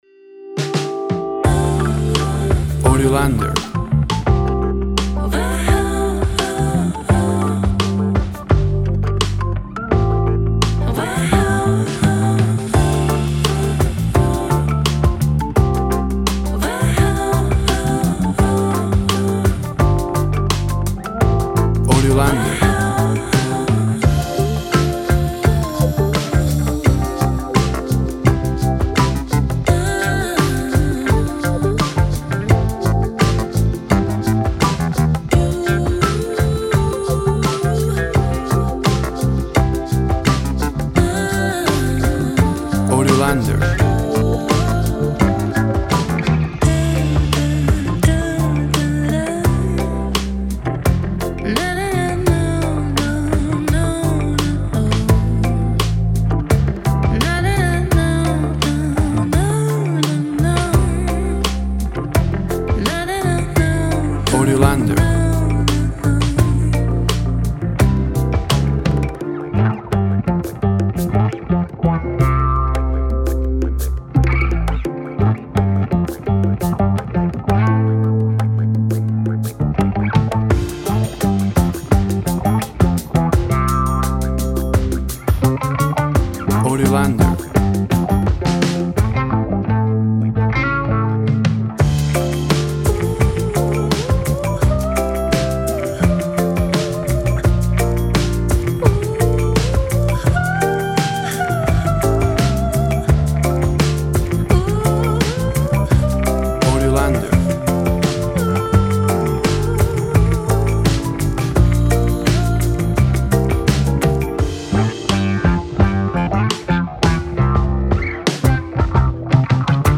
WAV Sample Rate 24-Bit Stereo, 44.1 kHz
Tempo (BPM) 85